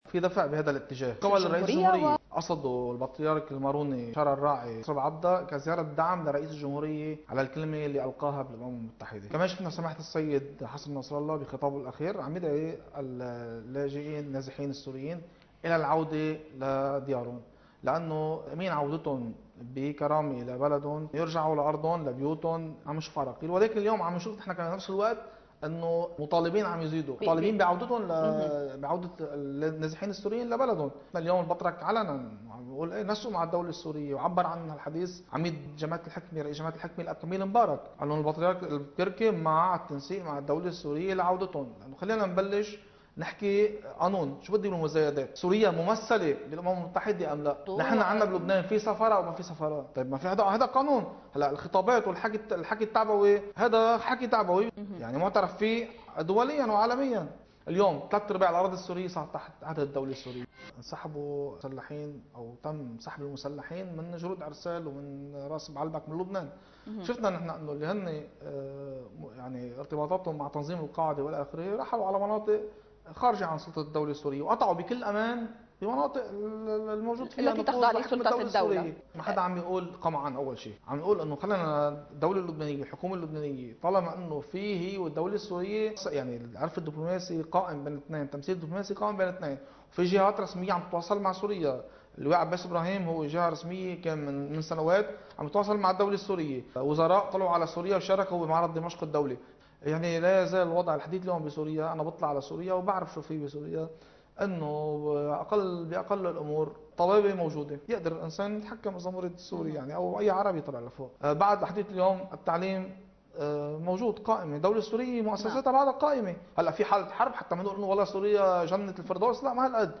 حديث